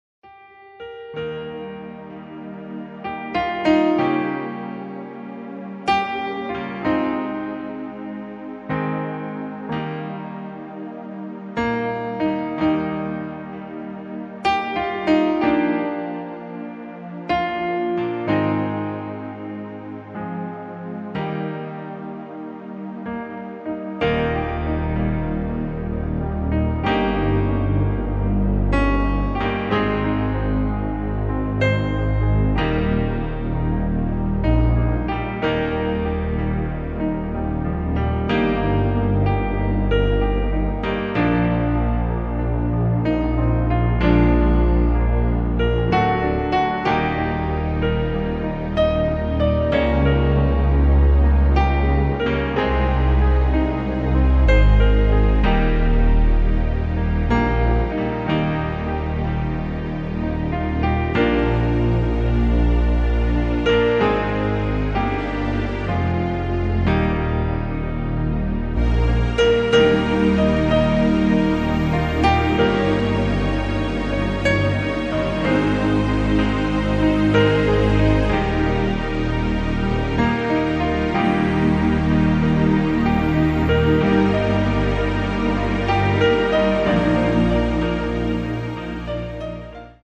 Klavierversion
• Art: Klavier Streicher Orchester (getragen, eher monströs)
• Das Instrumental beinhaltet NICHT die Leadstimme
Klavier / Streicher